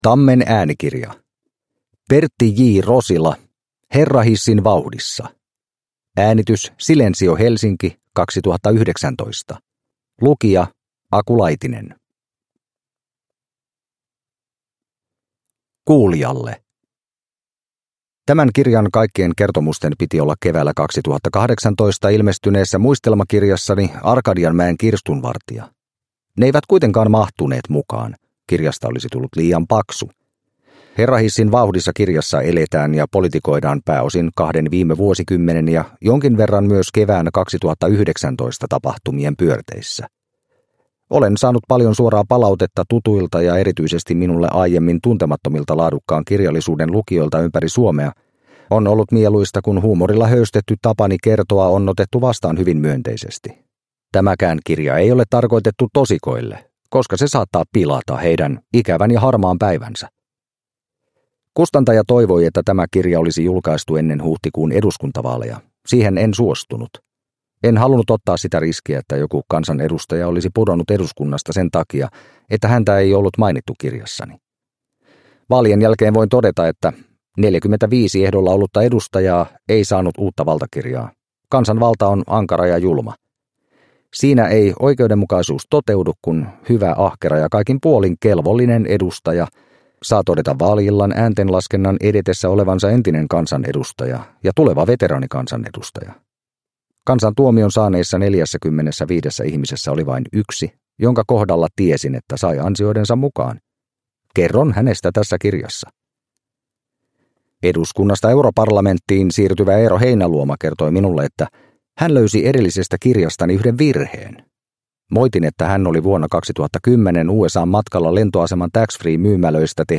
Herrahissin vauhdissa – Ljudbok – Laddas ner